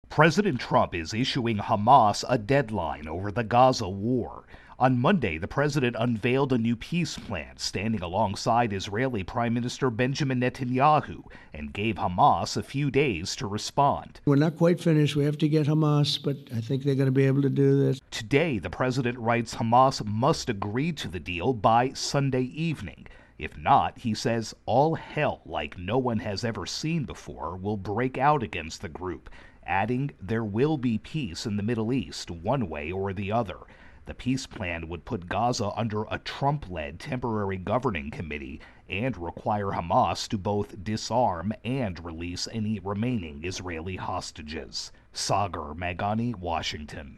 reports on President Trump's deadline for Hammas to agree on a deal for ending the Gaza war.